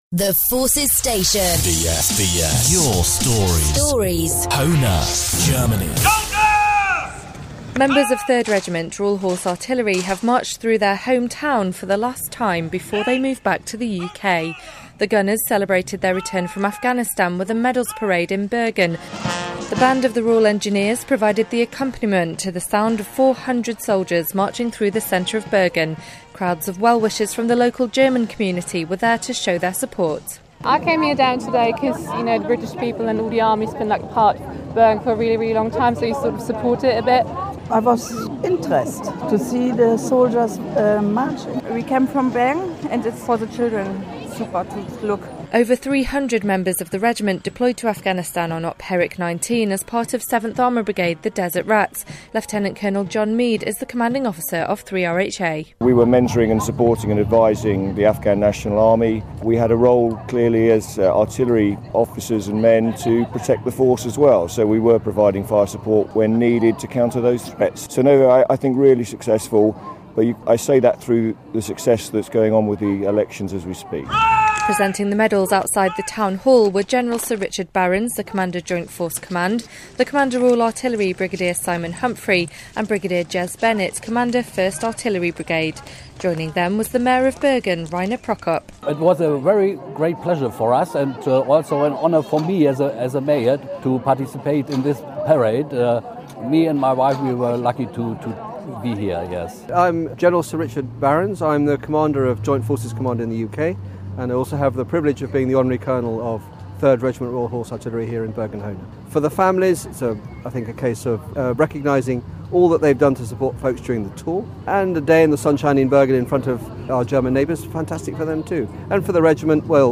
The gunners celebrated their return from Afghanistan with a medals parade in Bergen, near their base in Hohne.